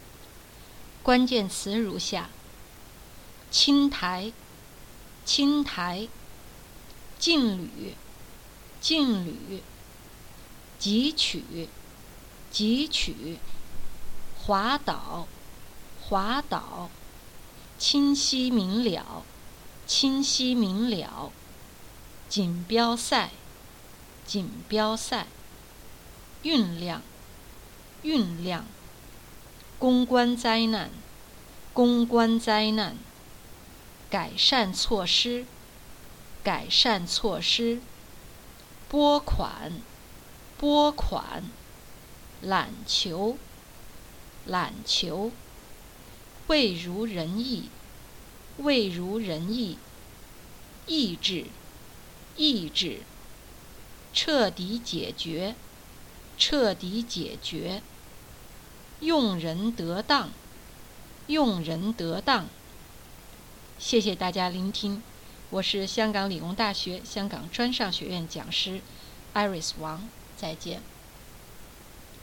重要詞彙朗讀 (普通話)